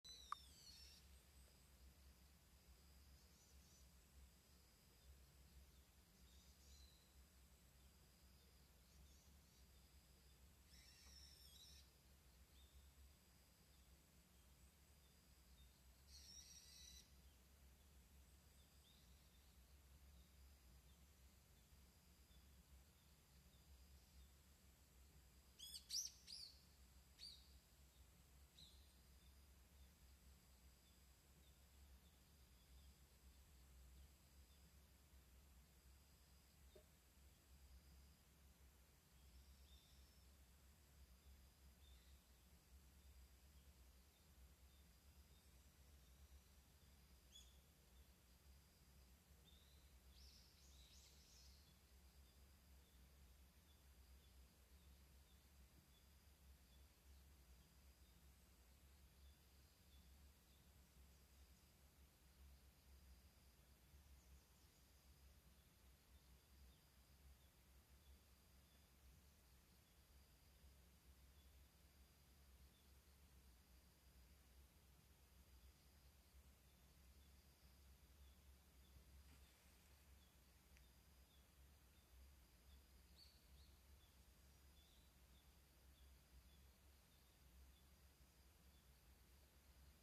環境音 山あいにて 鳥や虫